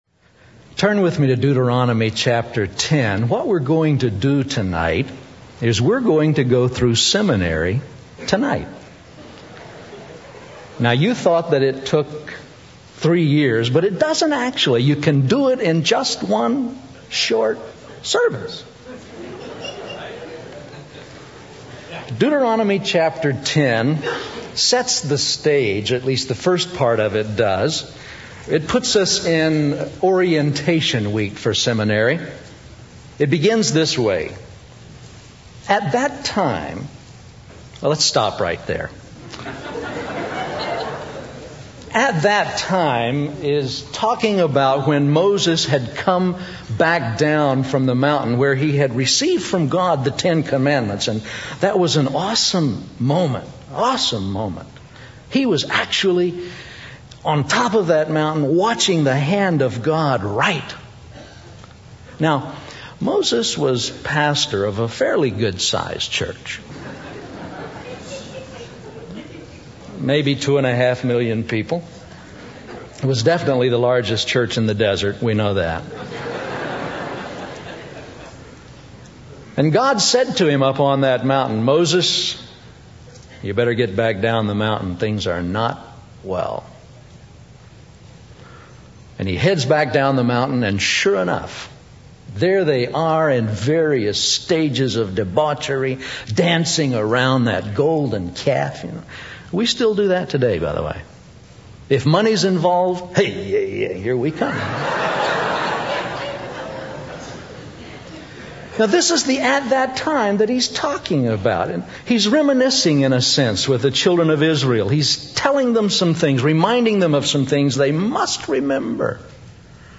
Taken from Deuteronomy 10, the work of the priesthood and its application for today’s Christian. An interactive memory rally.